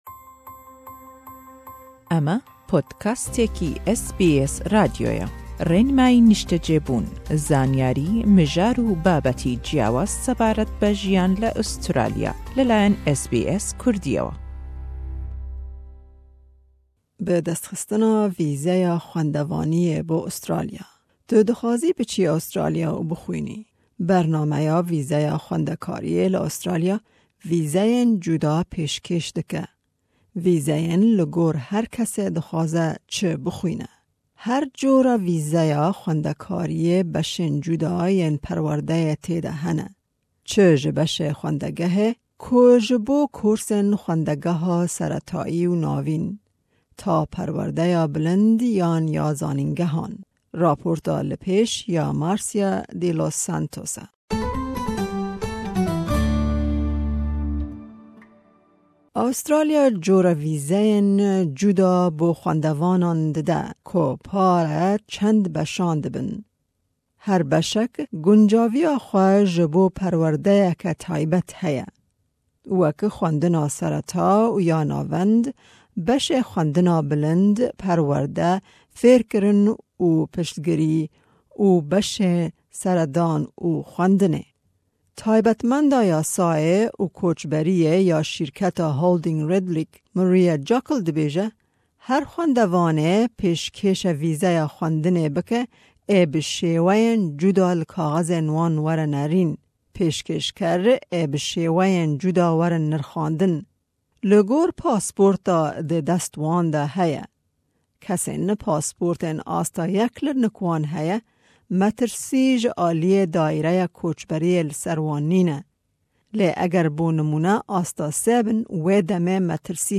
Raport bi Îngilîzî û Kurdî ye.